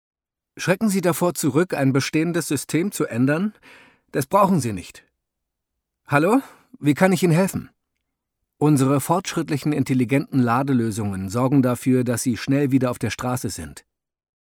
hell, fein, zart, markant, sehr variabel
Dialog
Audio Drama (Hörspiel), Doku